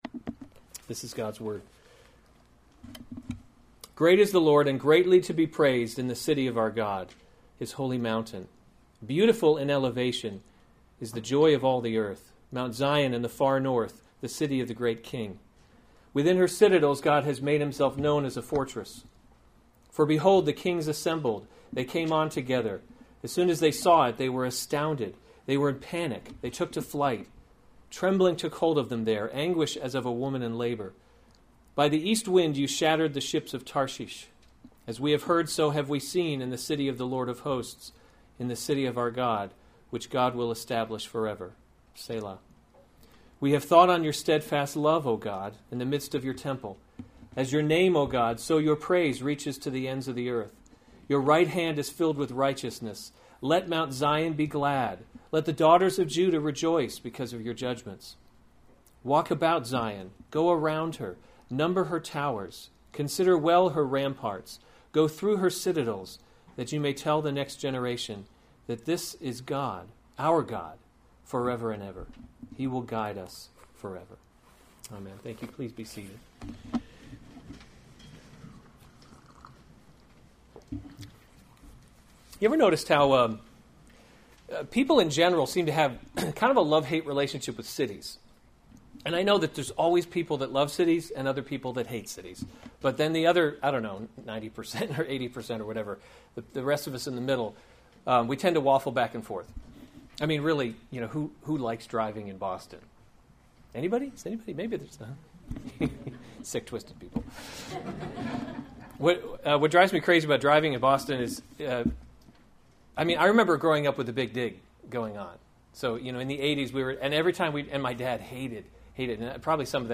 August 13, 2016 Psalms – Summer Series series Weekly Sunday Service Save/Download this sermon Psalm 48 Other sermons from Psalm Zion, the City of Our God A Song.